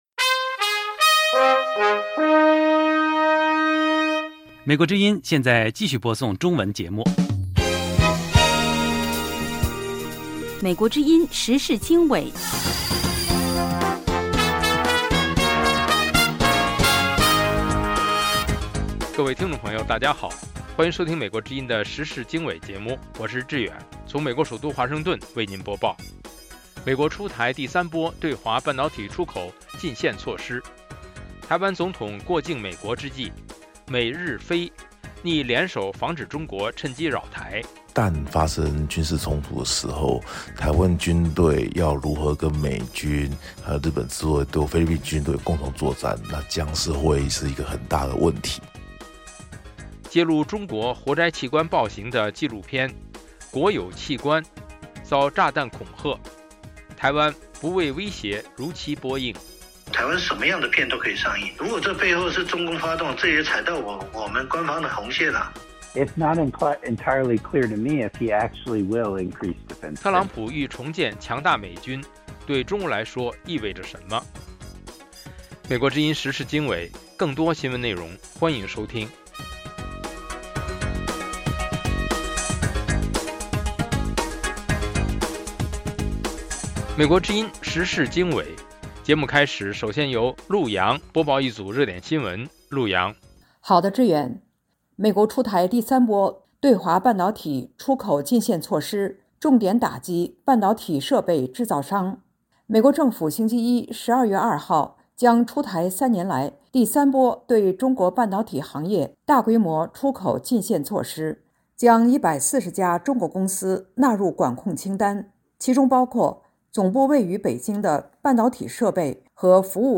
美国之音中文广播《时事经纬》重点报道美国、世界和中国、香港、台湾的新闻大事，内容包括美国之音驻世界各地记者的报道，其中有中文部记者和特约记者的采访报道，背景报道、世界报章杂志文章介绍以及新闻评论等等。